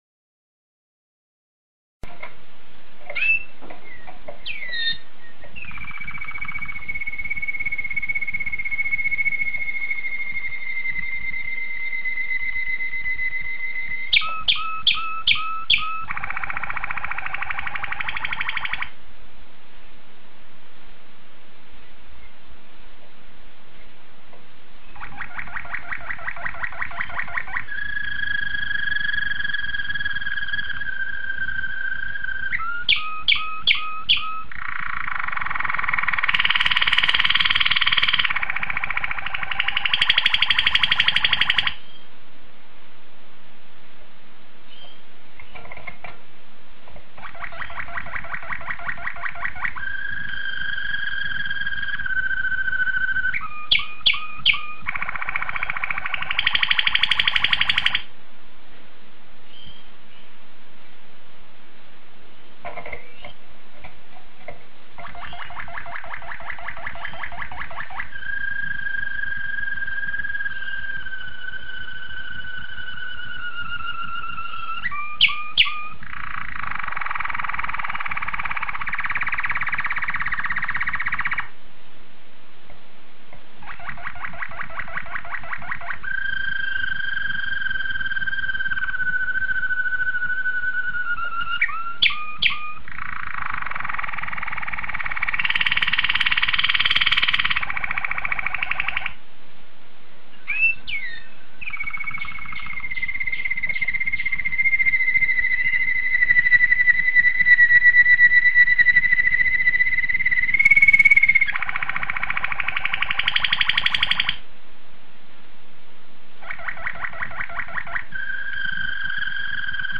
Malinua Kanaryası Ötüşü